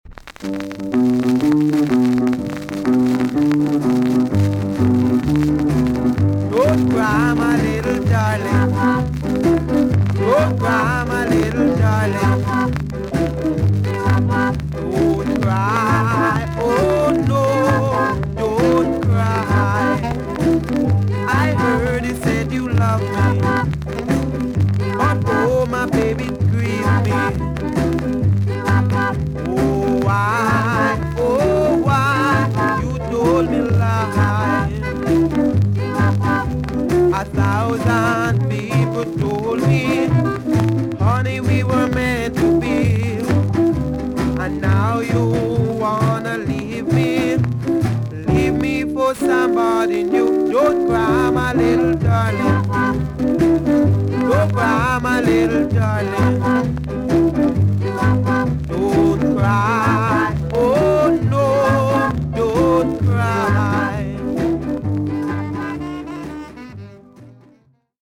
TOP >SKA & ROCKSTEADY
VG ok 全体的に軽いチリノイズが入ります。
1960 , UK , RARE , NICE VOCAL TUNE!!